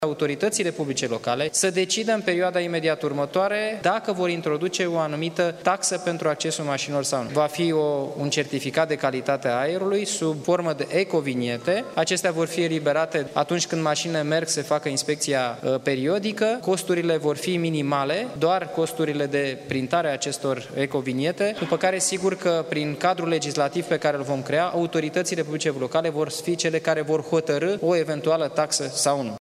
Vicepremierul Daniel Constantin a prezentat, astăzi, în cadrul coaliţiei de guvernare o variantă pentru crearea unui nou cadru legislativ pentru taxarea maşinilor care depăşesc noxele legale: